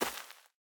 Minecraft Version Minecraft Version 25w18a Latest Release | Latest Snapshot 25w18a / assets / minecraft / sounds / block / hanging_roots / step2.ogg Compare With Compare With Latest Release | Latest Snapshot
step2.ogg